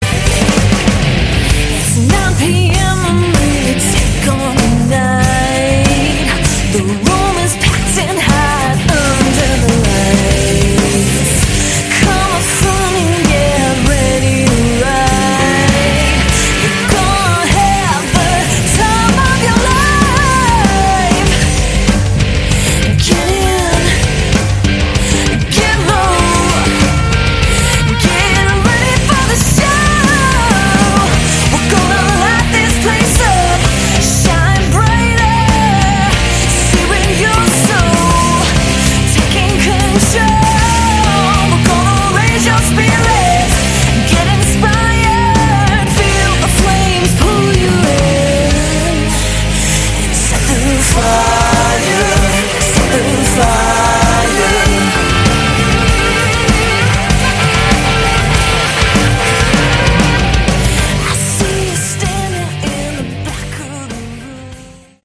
Category: Modern Hard Rock
lead vocals, bass
guitar, vocals
drums
keyboards